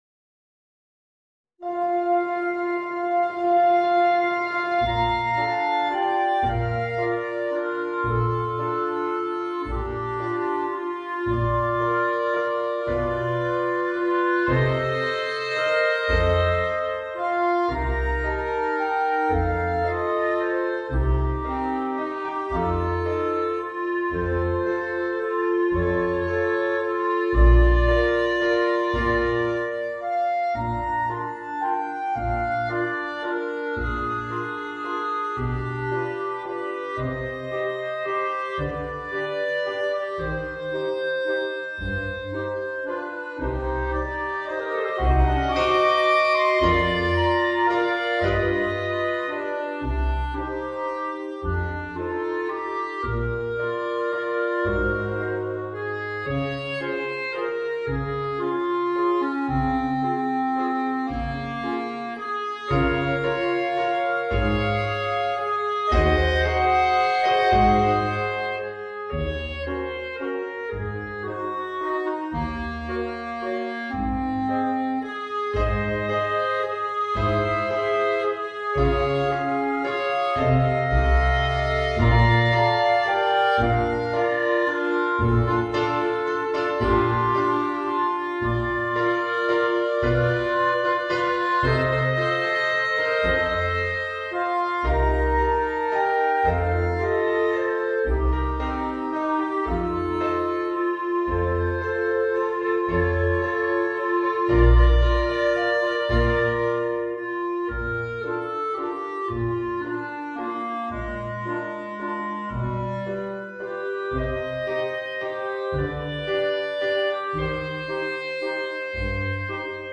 Voicing: 4 Clarinets and Rhythm Section